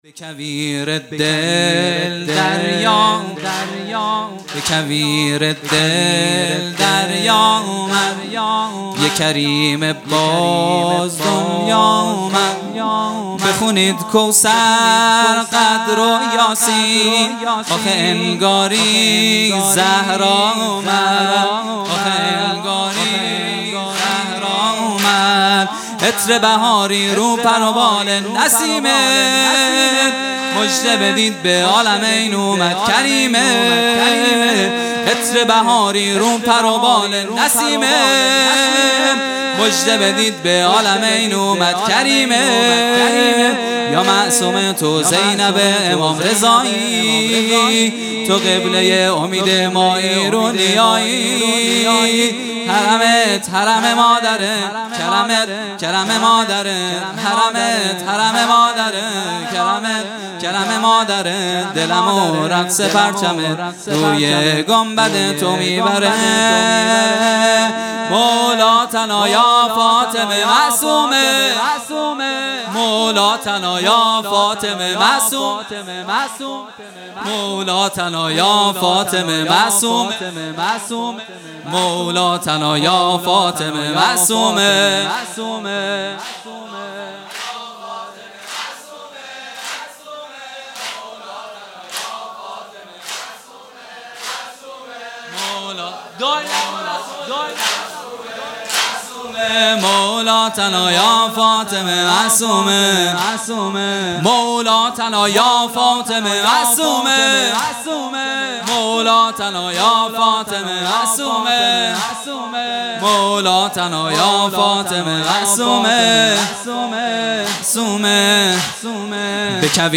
سرود | به کویر دل دریا اومد | ۱۲ خرداد ۱۴۰۱
جلسۀ هفتگی | ولادت حضرت معصومه(سلام الله علیها) | پنجشنبه ۱۲ خرداد ۱۴۰۱